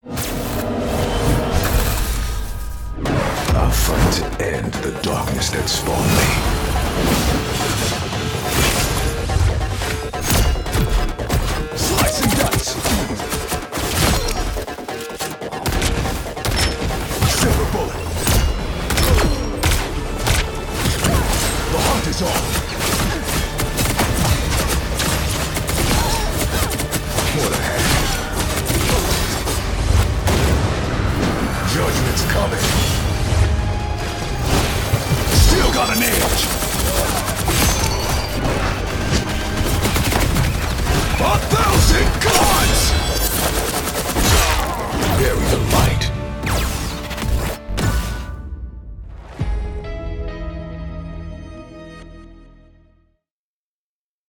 The Voice of Blade Download This Spot